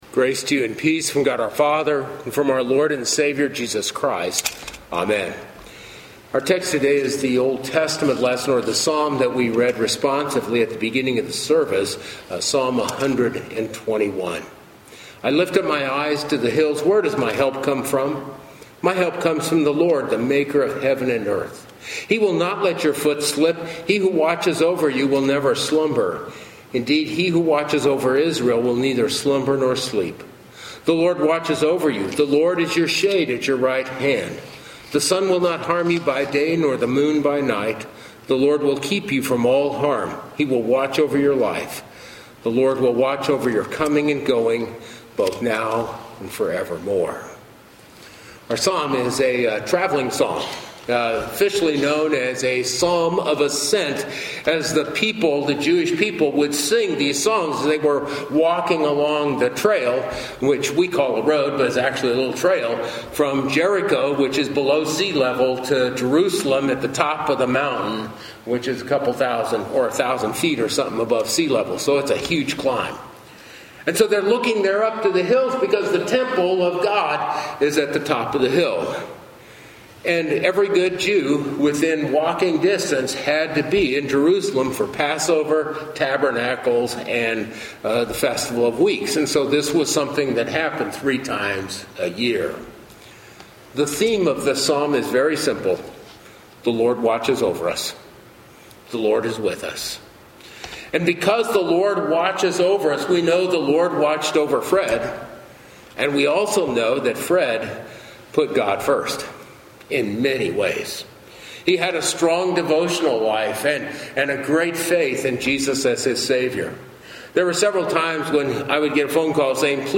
Funeral Sermons — Holy Trinity Lutheran Church